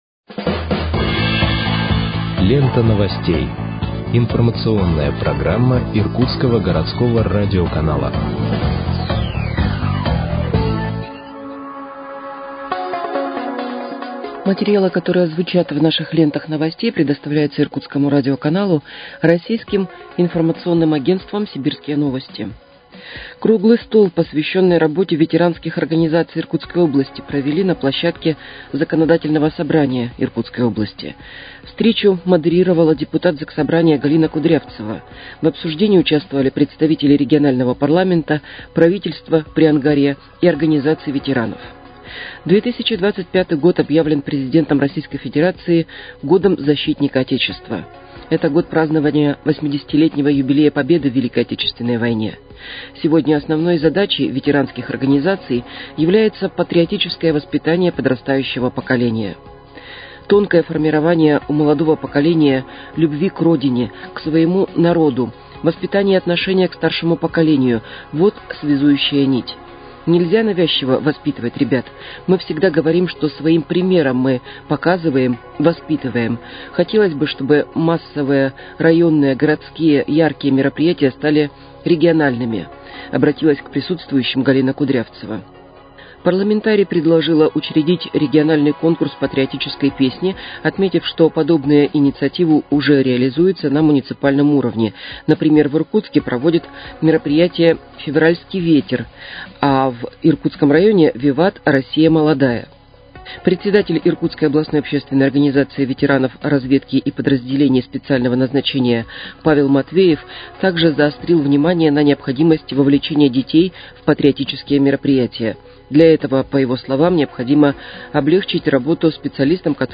Выпуск новостей в подкастах газеты «Иркутск» от 27.03.2025 № 1